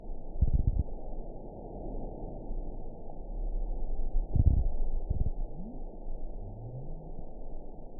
event 915794 date 12/14/22 time 05:01:28 GMT (2 years, 5 months ago) score 8.13 location TSS-AB04 detected by nrw target species NRW annotations +NRW Spectrogram: Frequency (kHz) vs. Time (s) audio not available .wav